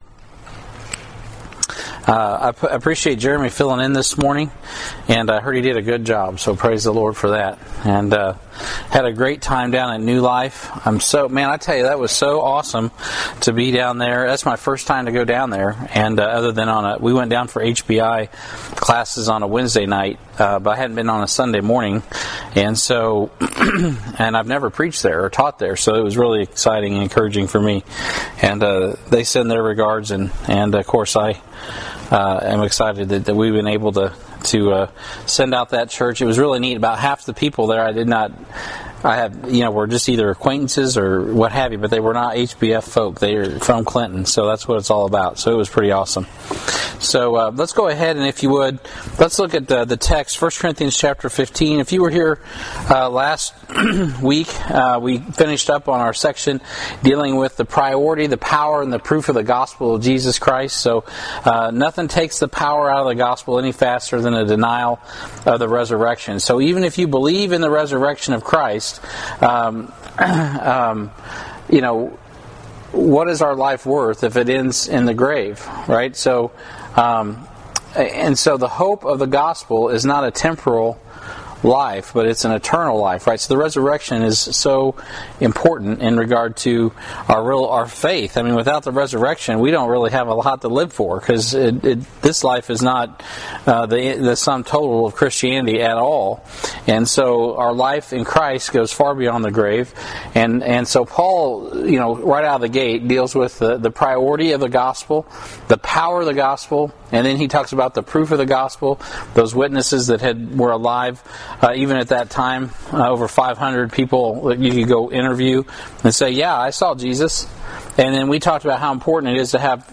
Sermons | Heartland Baptist Fellowship